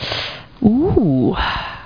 00034_Sound_Ooh.mp3